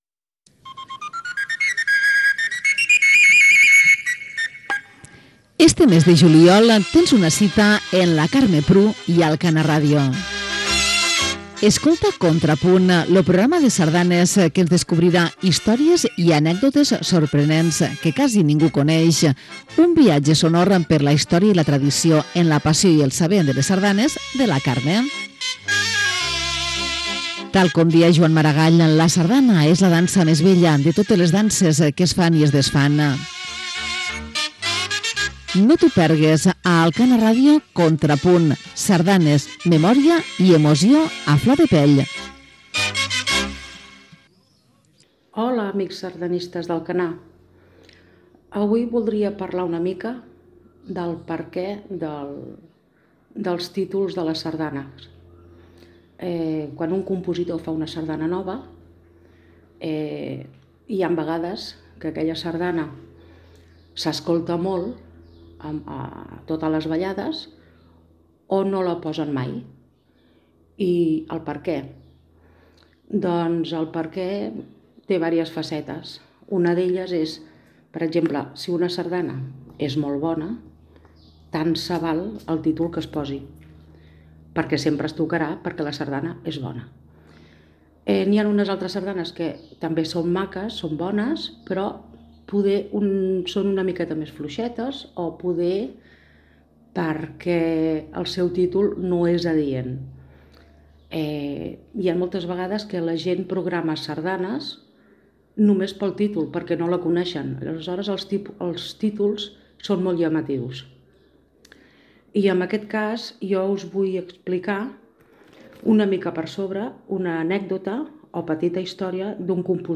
Careta, presentació del programa sobre el món de la sardana. Comentari sobre els títols de les sardanes.
Musical